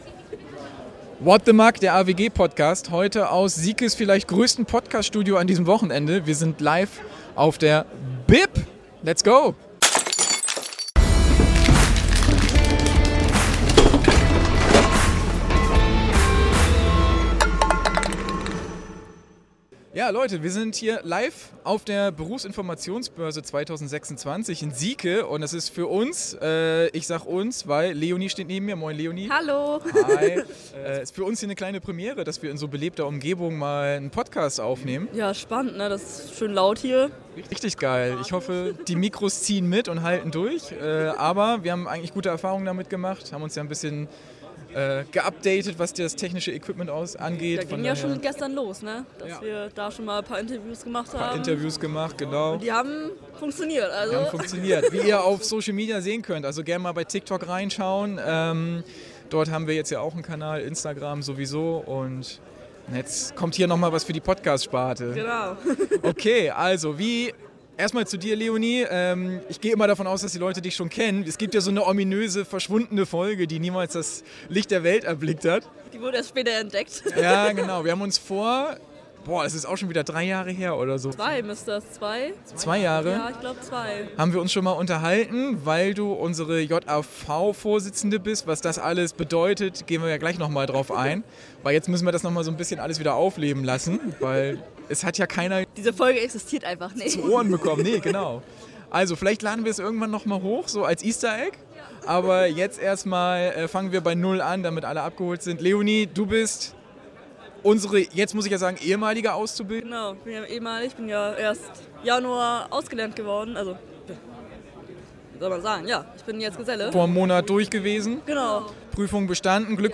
#12 Live von unserem Stand auf der BiB 2026! ~ What The Muc Podcast
Premiere bei "What The Muc": Wir verlassen unsere sichere Podcast-Umgebung und machen die Berufsinformationsbörse in Syke unsicher.